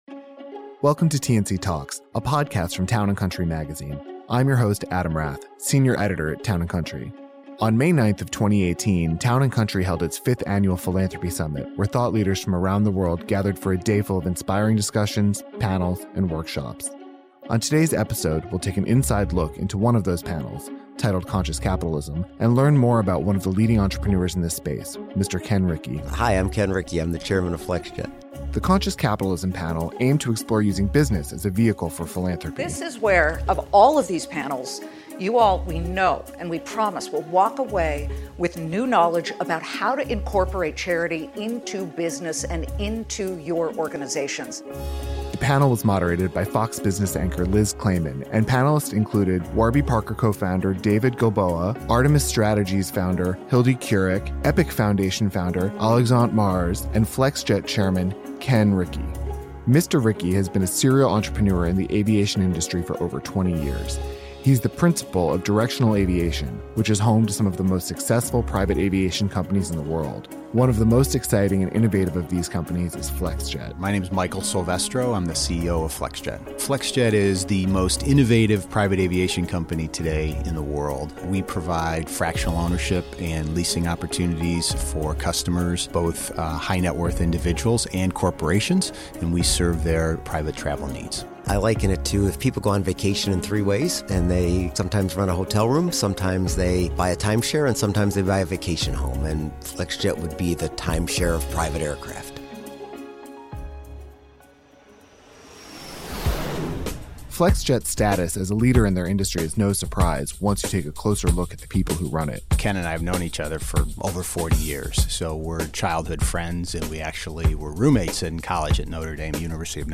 T&C Talks features interviews, insights, and stories surrounding our core values including travel, jewelry, the Royals, philanthropy, scandal and culture.